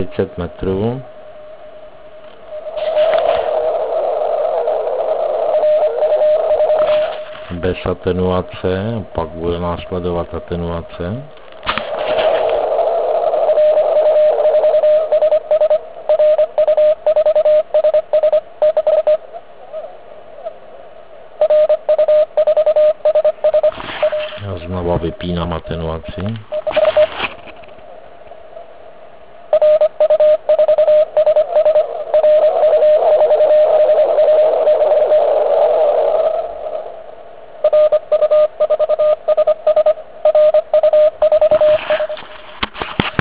Jeví se nám to např. jako nedefinovatelný šum (směs všech produktů).
Pásmo 40m a nebo pásmo 30m.
Ukázka intermodulační neodolnosti a vliv attenuátoru (*.wav 175 KB)
Zkrátka bez attenuátoru si moc na NE612 nezaposlouchám.